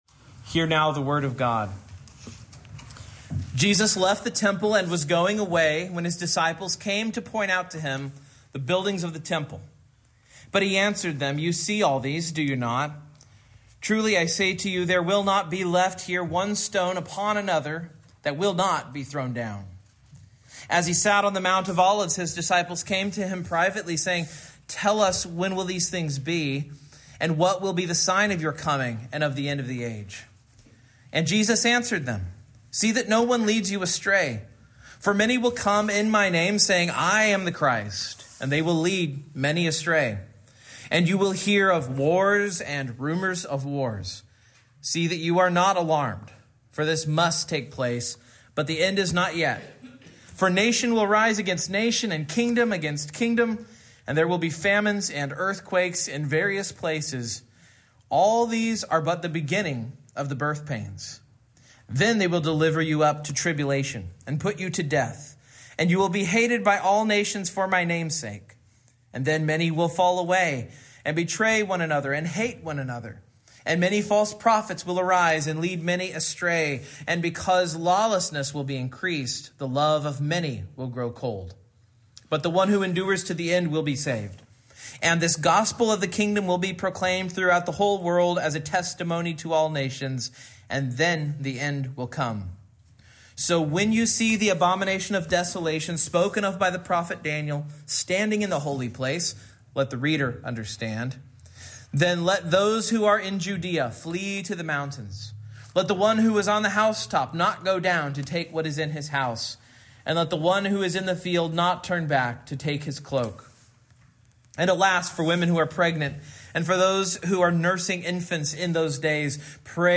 Matthew 24:1-24:35 Service Type: Morning Main Point